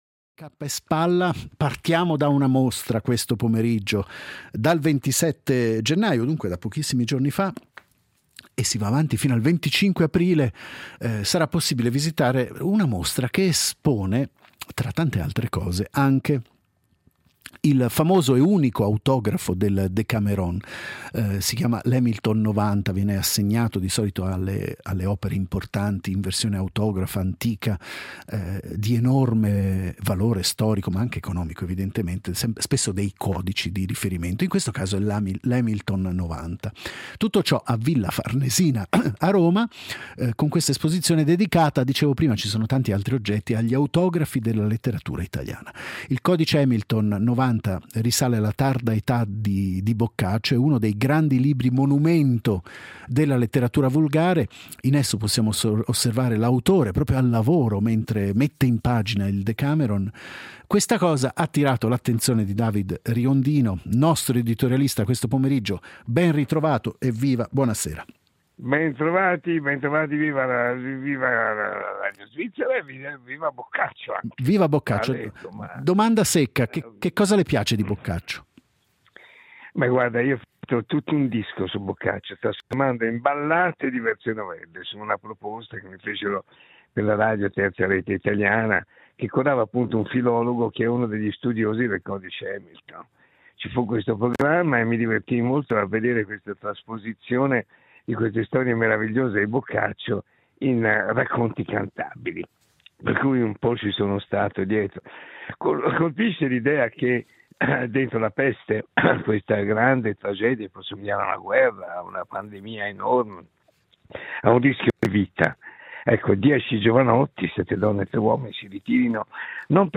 L’editoriale del giorno firmato da David Riondino